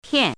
tiàn zhèn
tian4.mp3